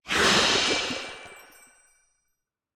Minecraft Version Minecraft Version latest Latest Release | Latest Snapshot latest / assets / minecraft / sounds / entity / glow_squid / squirt3.ogg Compare With Compare With Latest Release | Latest Snapshot
squirt3.ogg